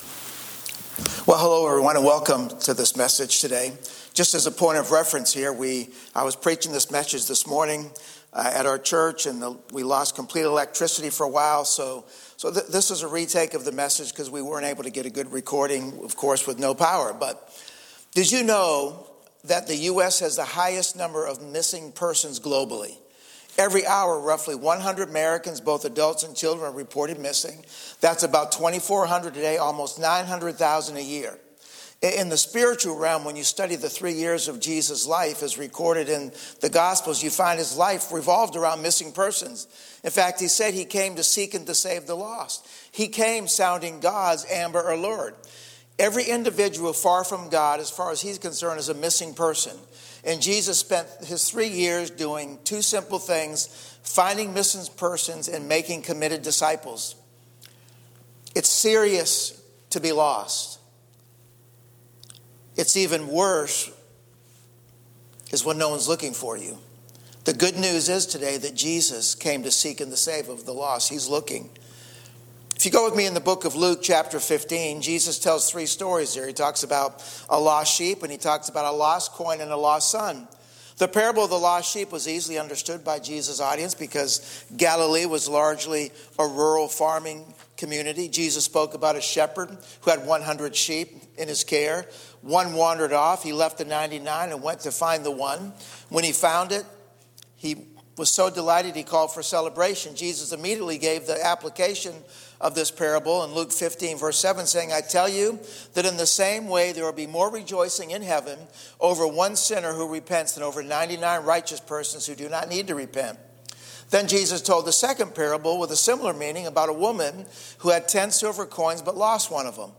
Note: Due to a power outage in the Sunday morning service, this message was recorded in a separate session on Sunday night.